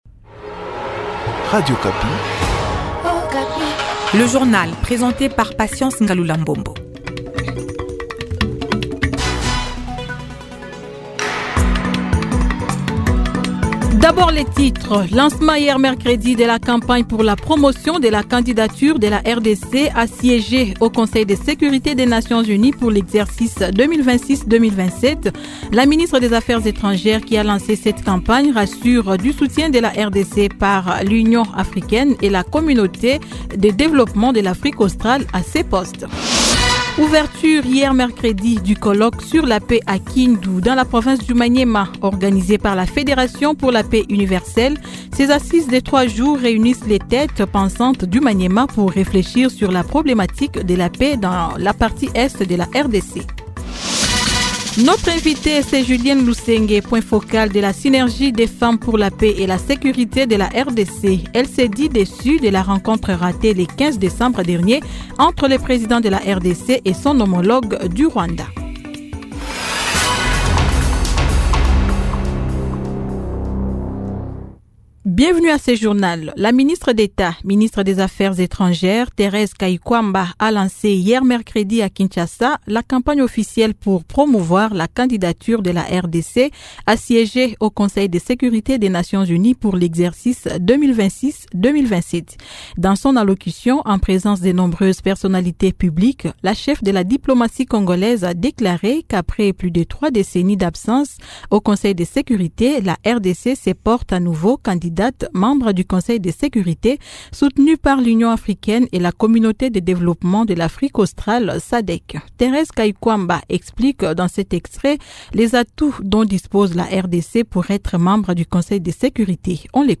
Journal matin 08H00